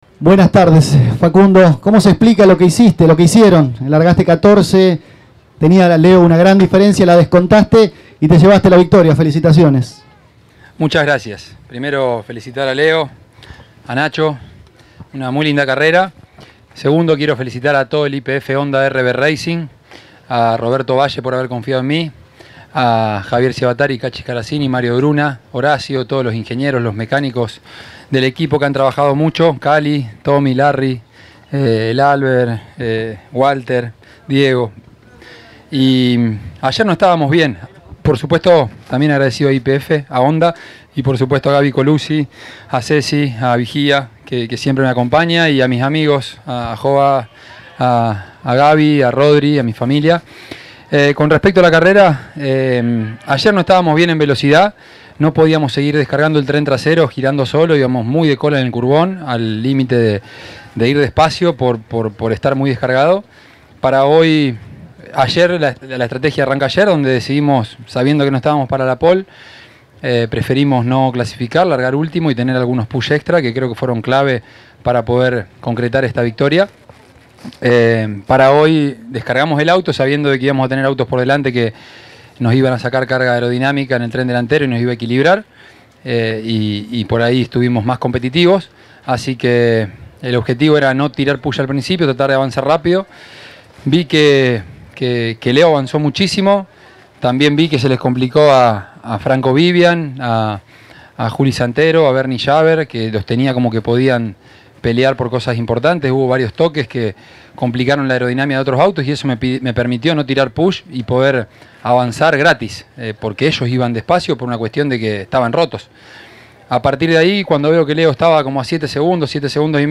El piloto de Las Parejas pasó por los micrófonos de Pole Position y habló sobre el triunfo conseguido en el «Templo de la Velocidad», donde pudo avanzar del 14° lugar y llevarse, en este caso, el segundo triunfo consecutivo en Rafaela.